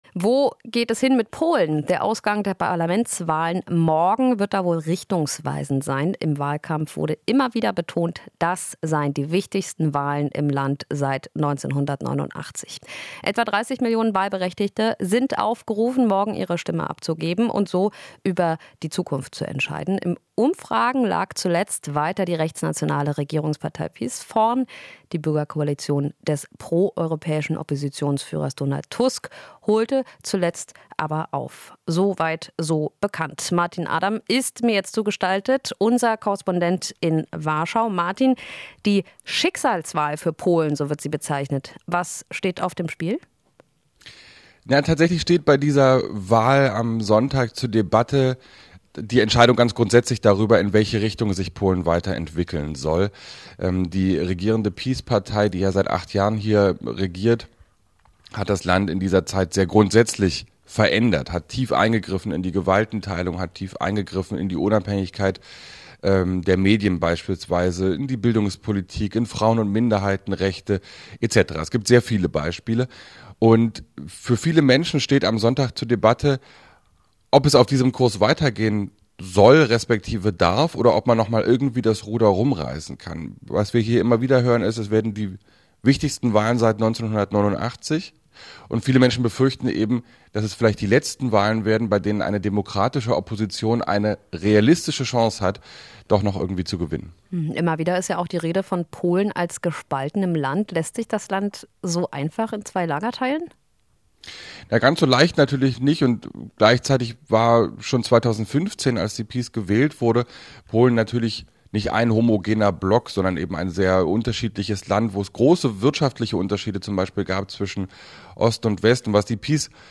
Interview - Knappes Ergebnis bei der Parlamentswahl in Polen erwartet